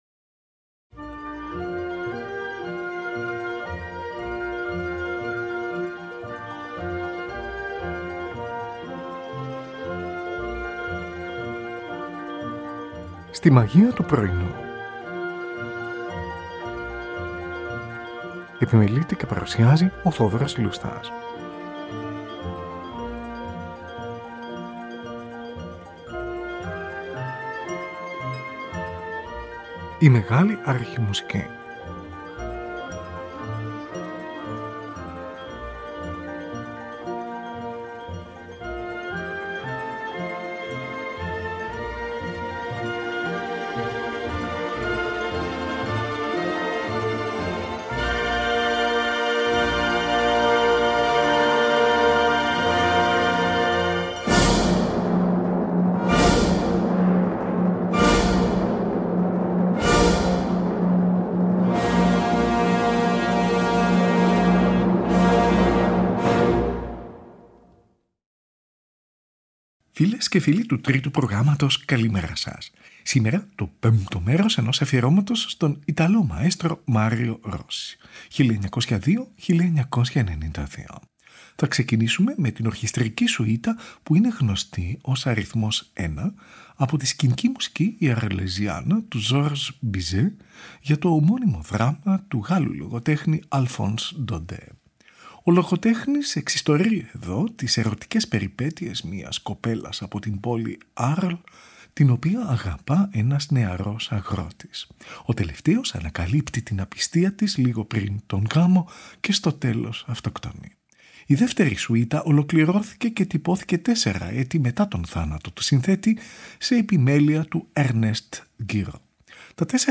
Τσέμπαλο-continuo